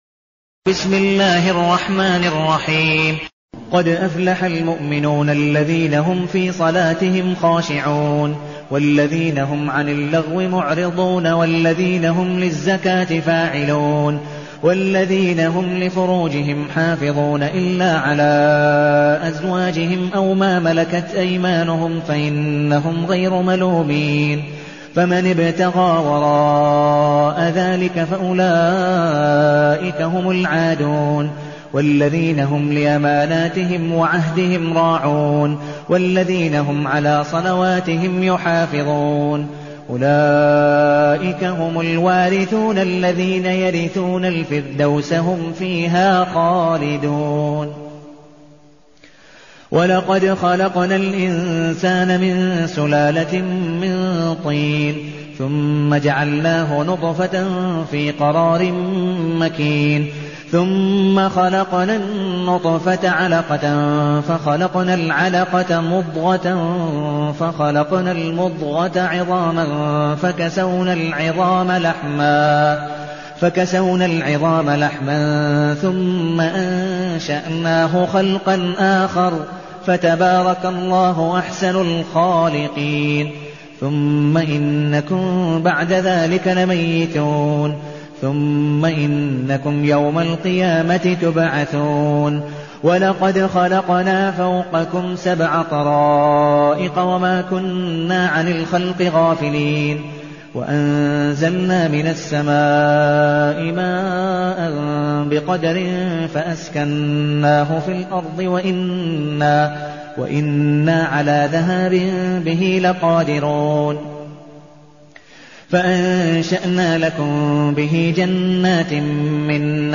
المكان: المسجد النبوي الشيخ: عبدالودود بن مقبول حنيف عبدالودود بن مقبول حنيف المؤمنون